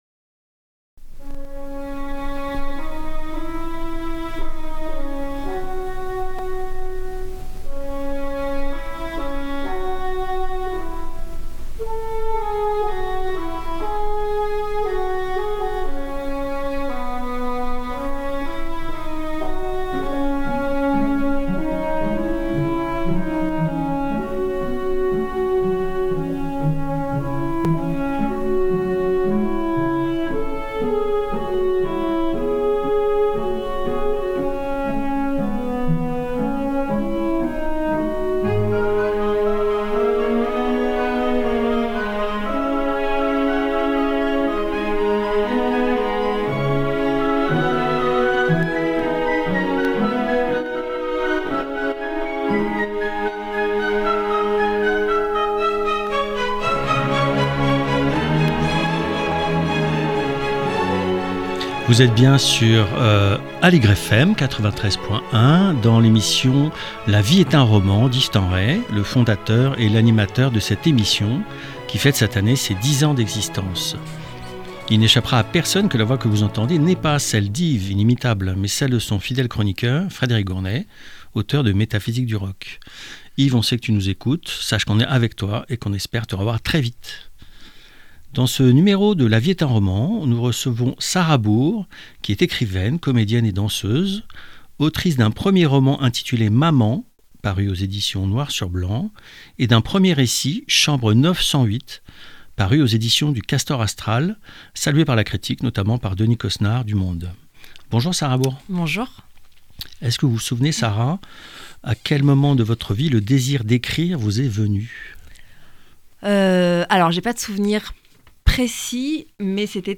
interviewée